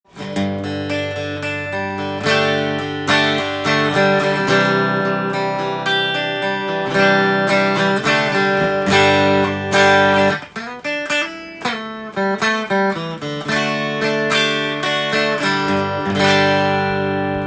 ピックアップにP90を搭載し
太すぎず、細すぎない絶妙なギターサウンドになっています。